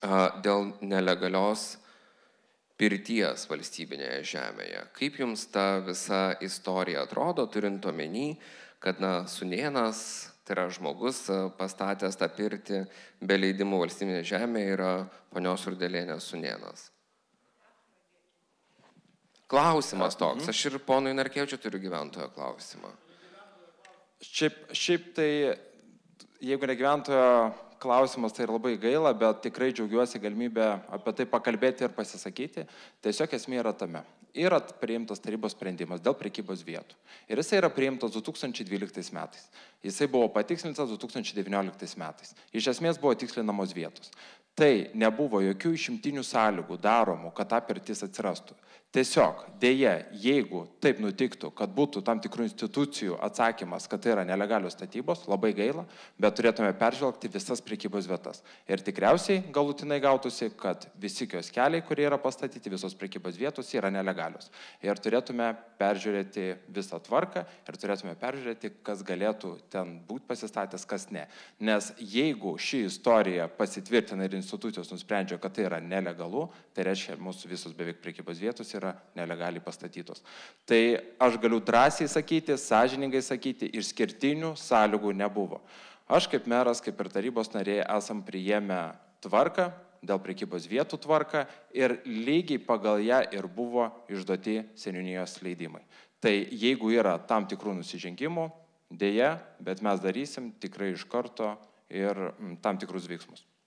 Trakų rajono meras Andriukas Šatevičius  „Žinau, ką renku“ debatuose prieš rinkimus, paklaustas apie pirtis, užsiplieskė, kad jos stovi savivaldybės nustatytose prekybos ir paslaugų vietose. Ir pažadėjo paaiškėjus pažeidimų faktui peržiūrėti visų vietų teisėtumą.